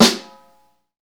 SNR XEXTS0CL.wav